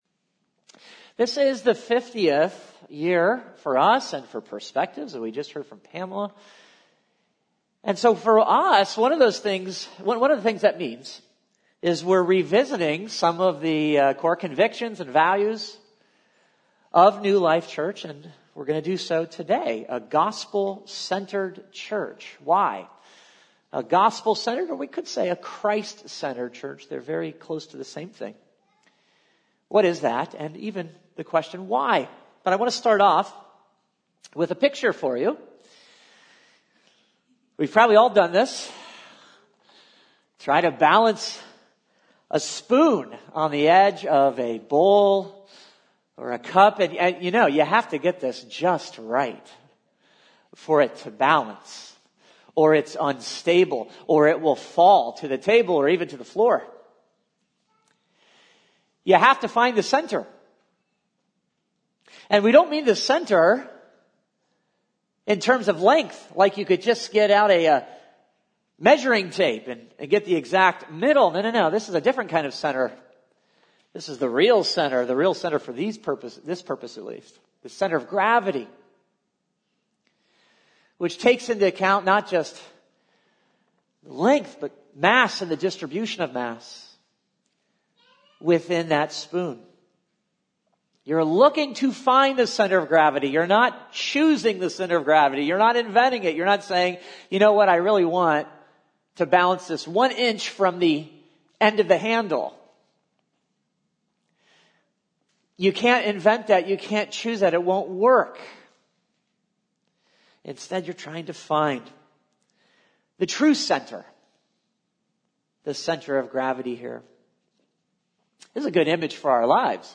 A message from the series "Other."